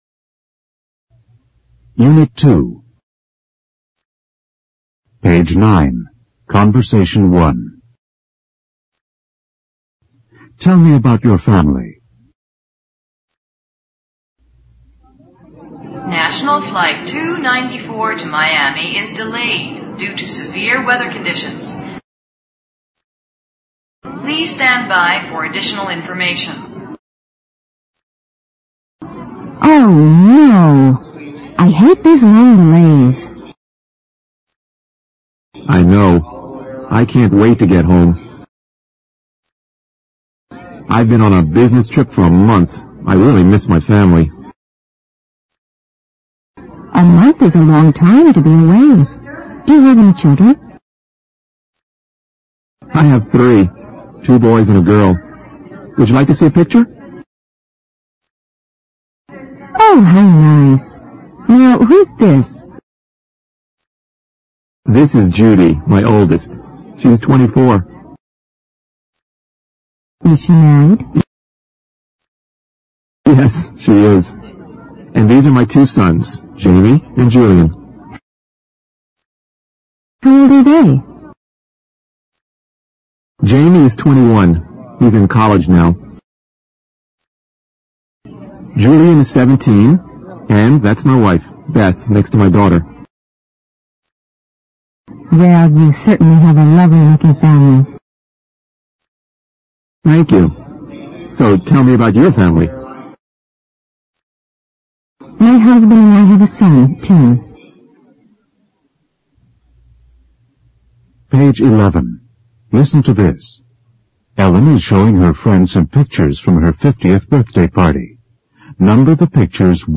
简单英语口语对话 unit2_conbersation1_new(mp3+lrc字幕)